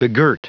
Prononciation du mot begirt en anglais (fichier audio)
Prononciation du mot : begirt